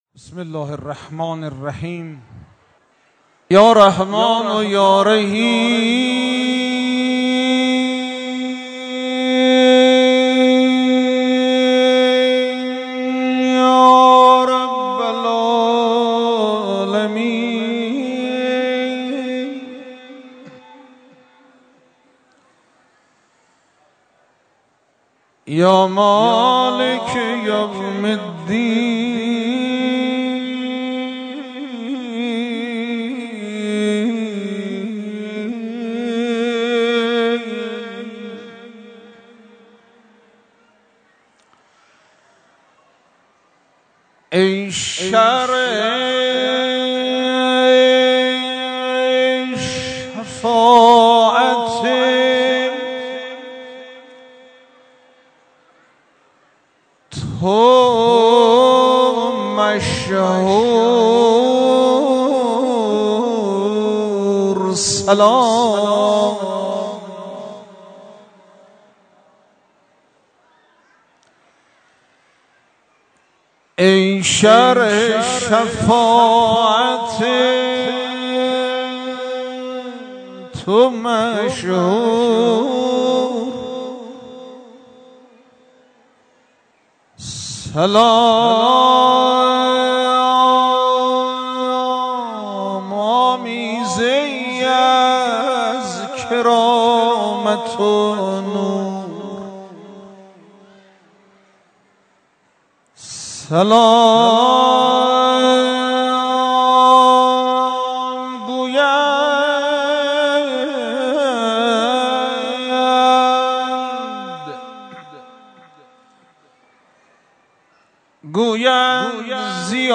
مداحی
در مسجد امام رضا(ع)